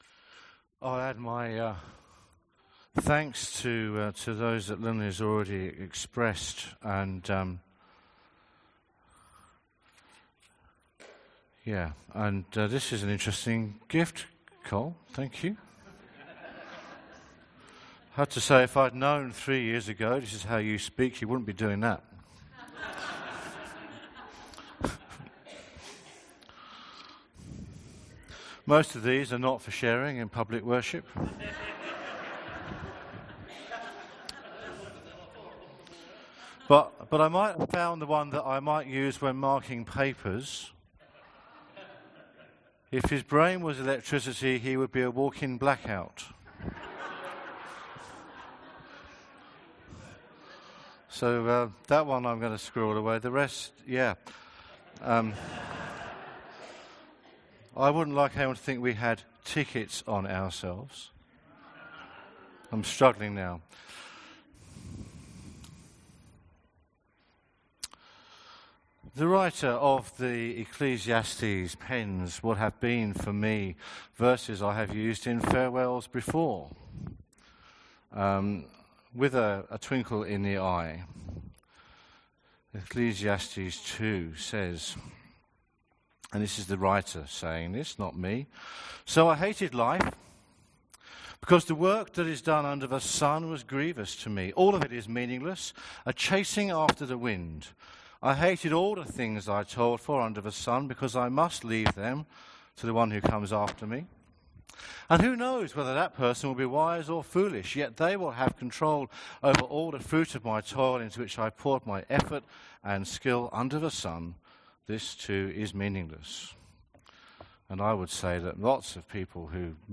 Sermon from the 10AM meeting at Newcastle Worship & Community Centre of The Salvation Army.